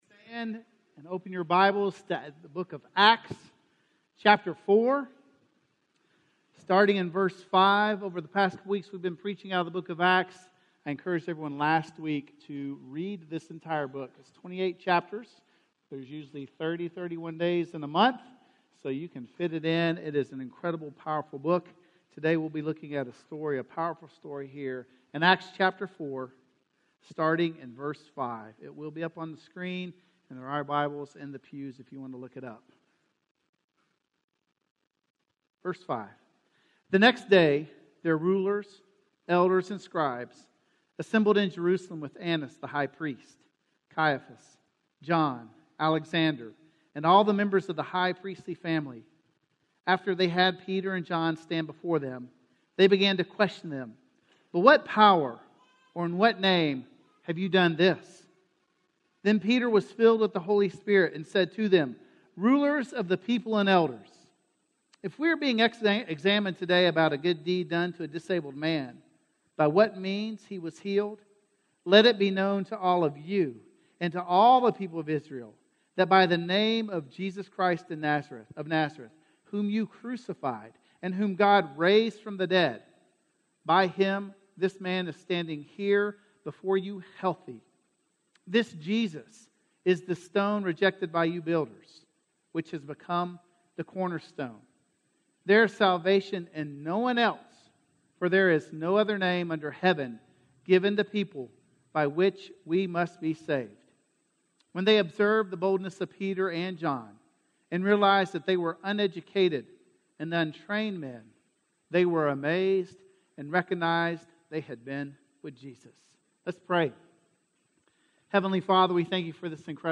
With Jesus - Sermon - Woodbine